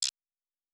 pgs/Assets/Audio/Sci-Fi Sounds/Interface/Error 07.wav at master
Error 07.wav